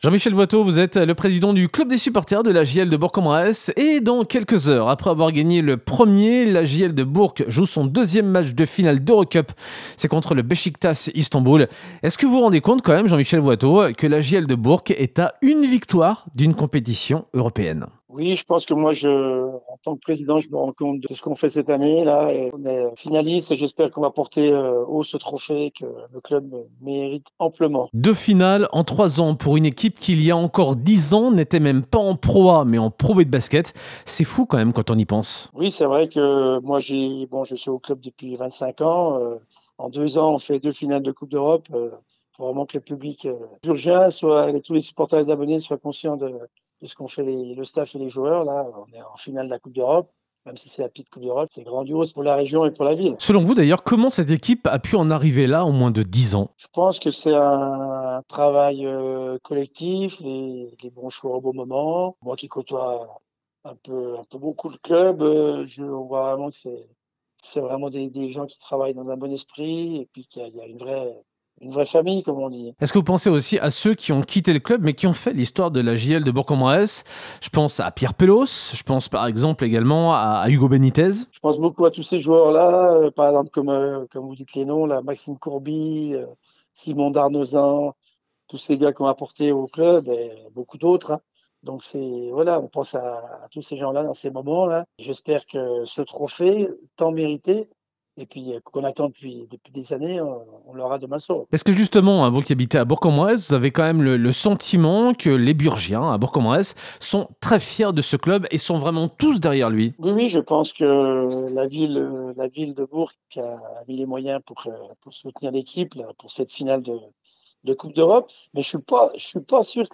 Interview de la Rédaction